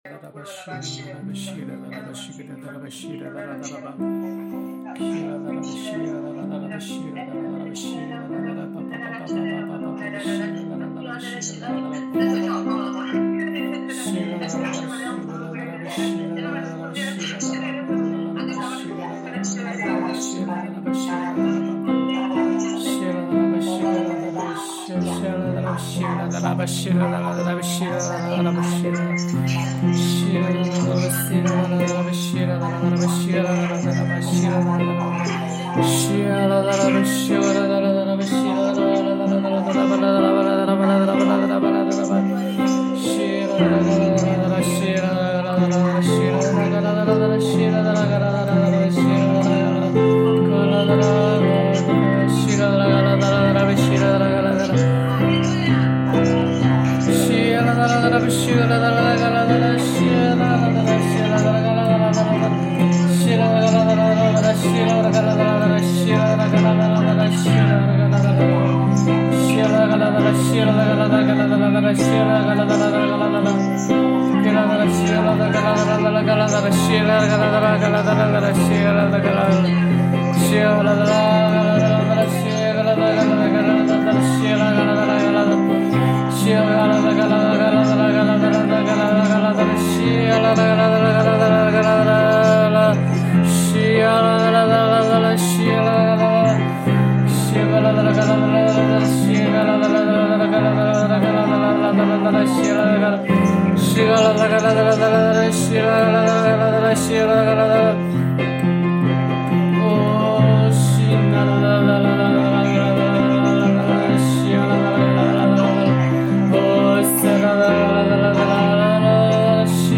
HAKA祷告敬拜MP3 启示性祷告： 持续祷告：祈求神的旨意成就在我们的身上，带领做新事！